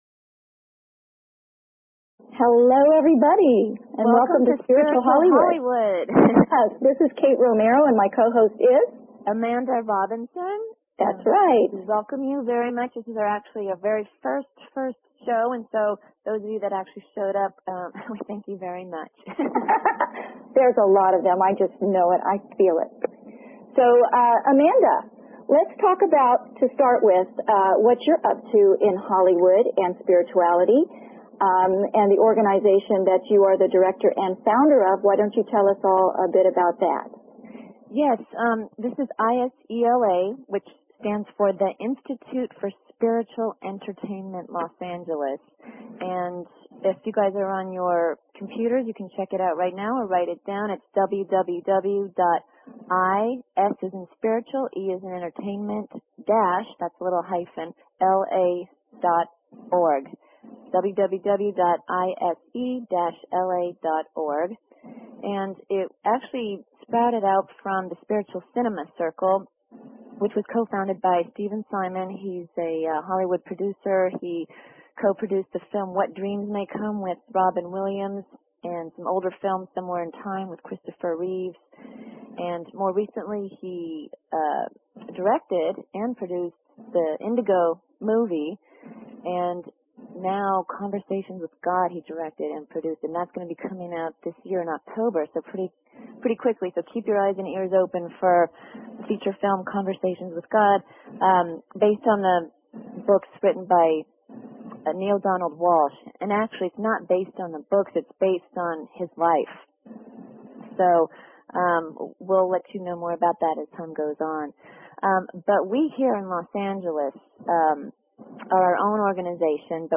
Talk Show Episode, Audio Podcast, Spiritual_Hollywood and Courtesy of BBS Radio on , show guests , about , categorized as